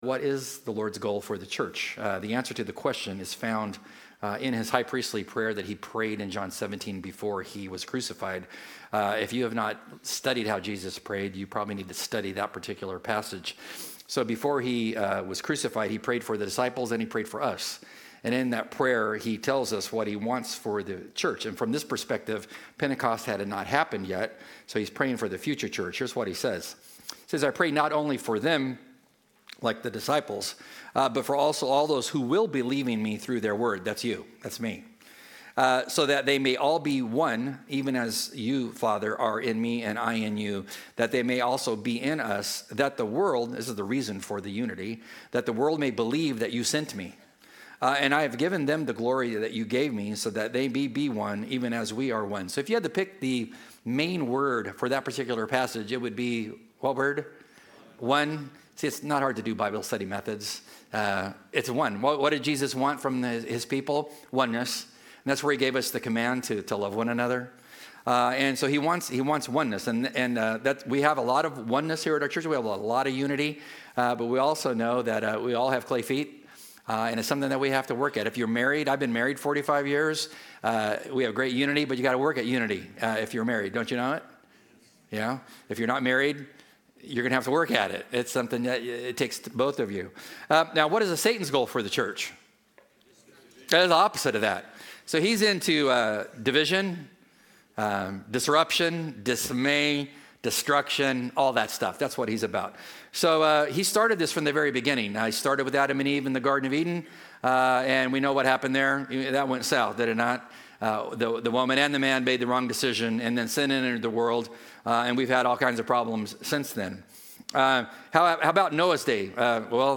As we continue in our "One Another" sermon series we take a look at what it looks like to be kind to one another and to forgive one another.